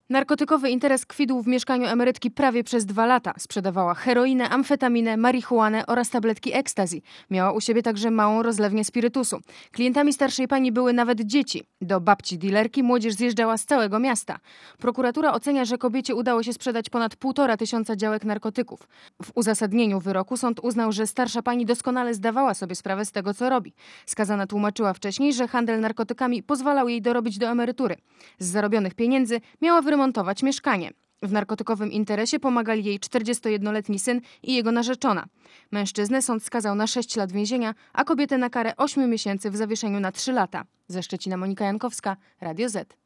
Ze Szczecina mówi reporter Radia Zet (1,31 MB)